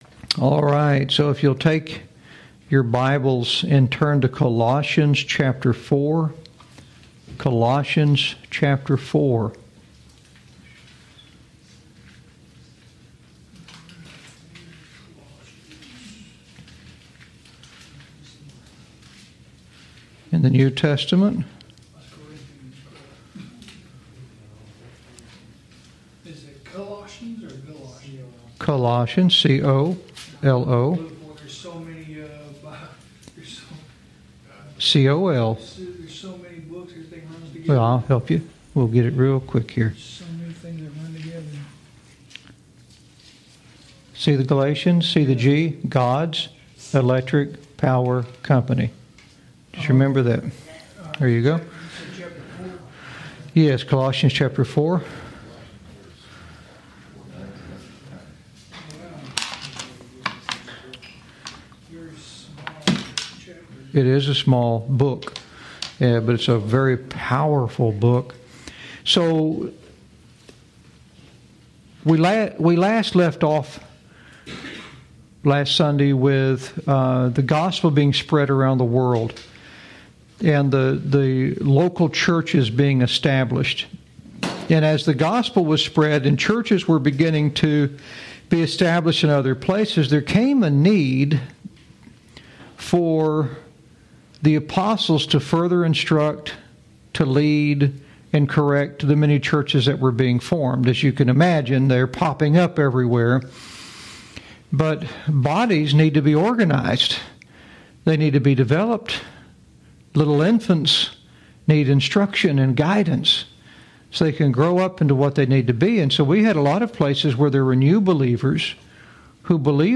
Lesson 4